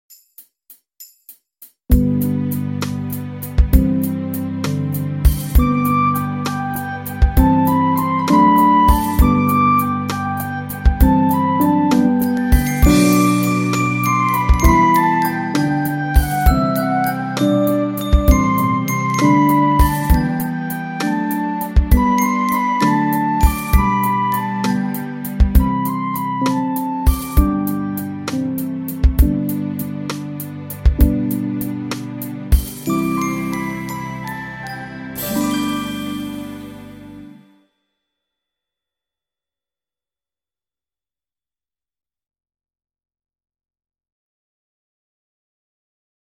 Accomp